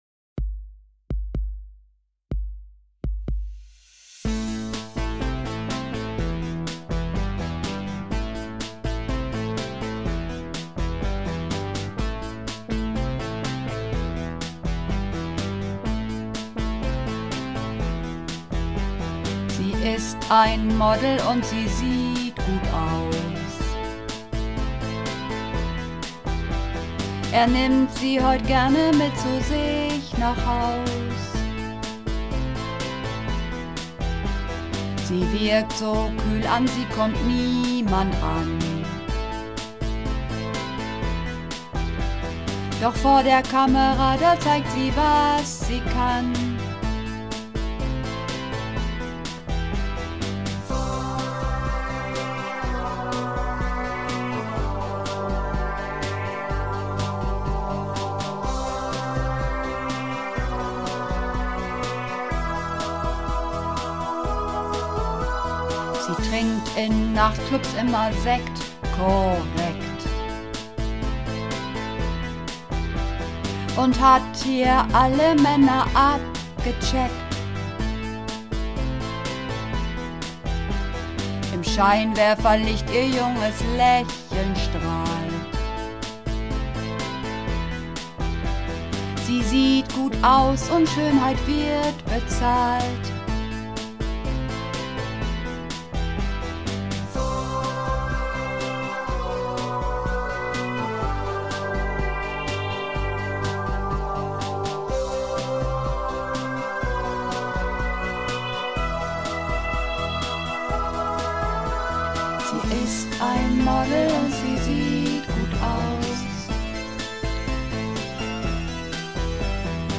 Übungsaufnahmen - Das Model
Das Model (Mehrstimmig)